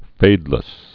(fādlĭs)